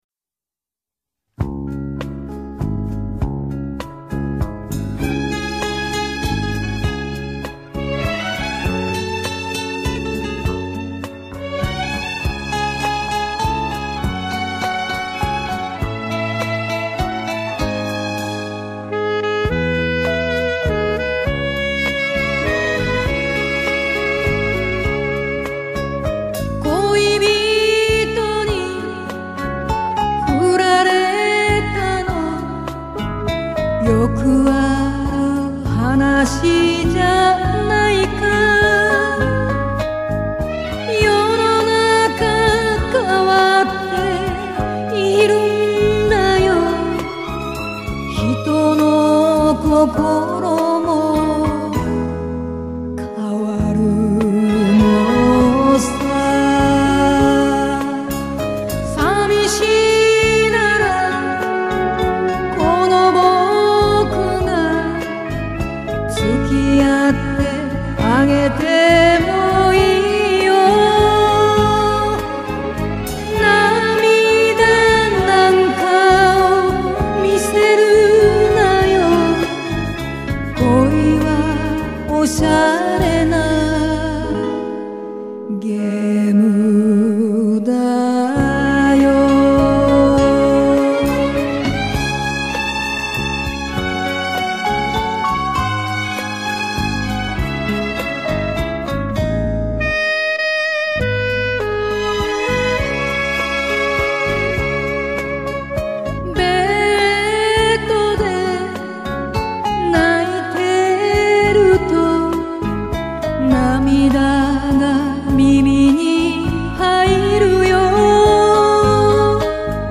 Кавер версия